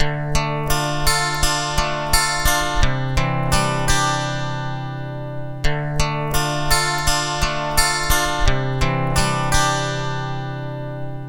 描述：磁带声
Tag: 85 bpm Acoustic Loops Guitar Acoustic Loops 1.90 MB wav Key : Unknown